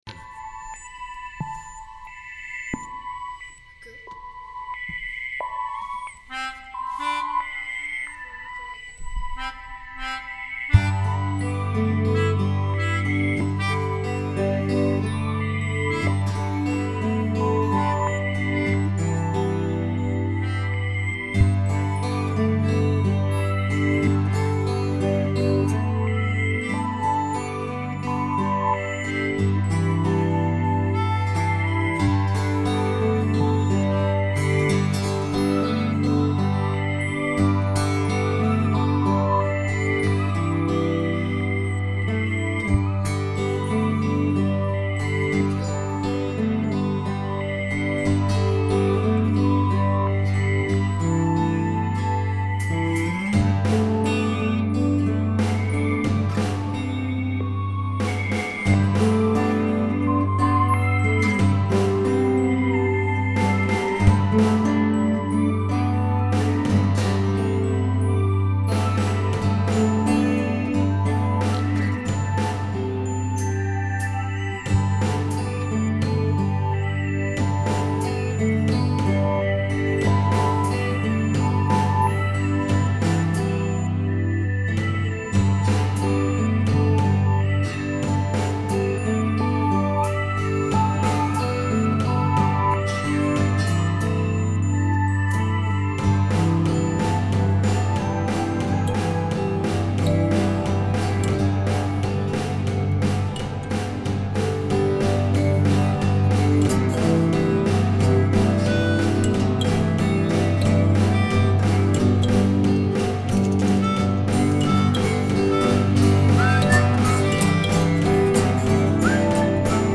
ギター、うた、笛、ピアノ、打楽器